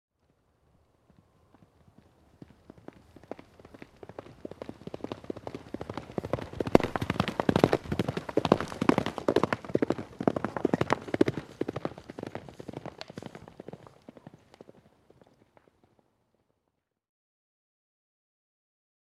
دانلود صدای اسب 9 از ساعد نیوز با لینک مستقیم و کیفیت بالا
جلوه های صوتی
برچسب: دانلود آهنگ های افکت صوتی انسان و موجودات زنده دانلود آلبوم انواع صدای شیهه اسب از افکت صوتی انسان و موجودات زنده